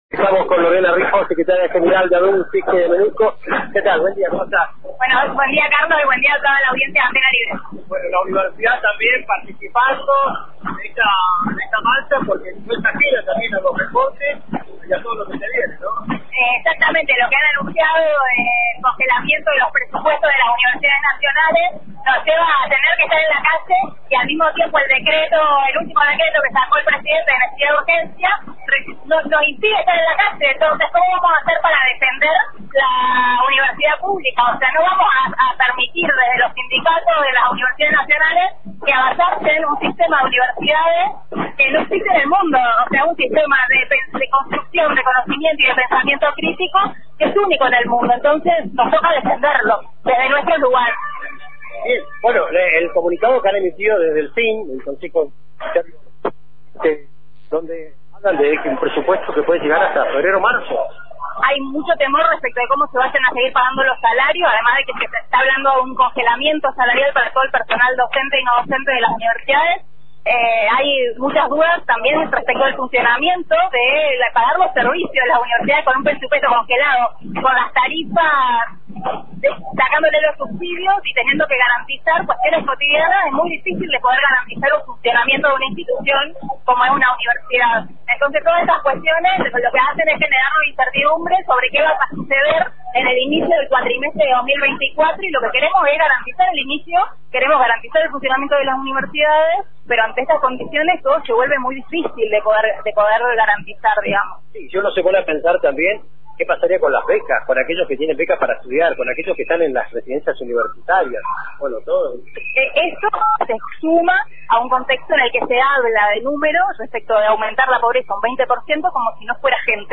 Antena Libre realizó una cobertura especial desde la movilización